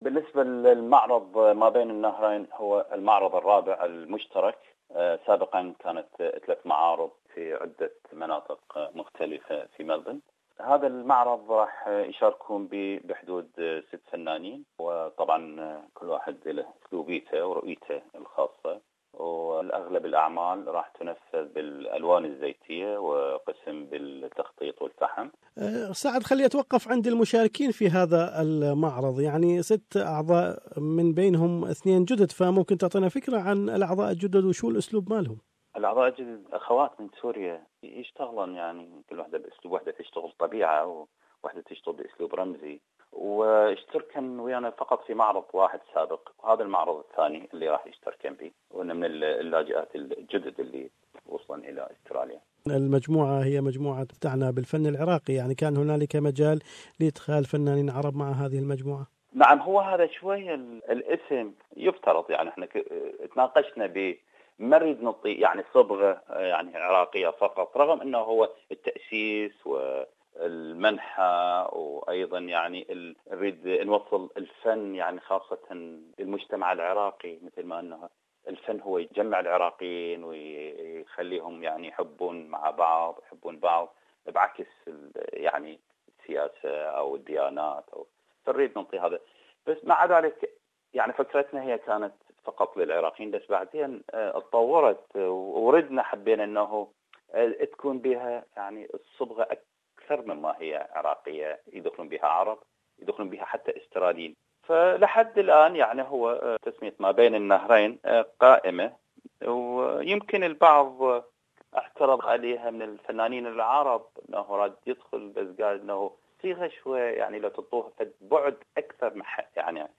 Australian Mesopotamia Arts Group is to organize a new arts exhibition in Melbourne under the theme "Spectra and Dialogue". More is in this interview with painter